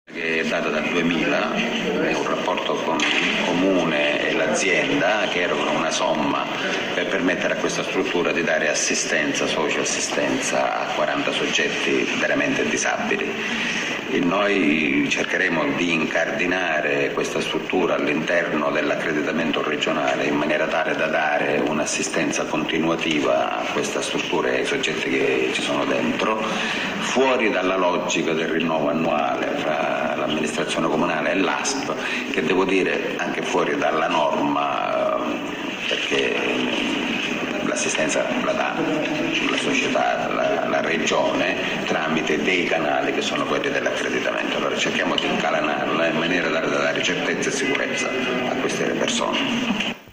Il dottor Sirna, ha spiegato meglio il tutto, intervistato dalla Rtp.